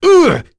Phillop-Vox_Attack3.wav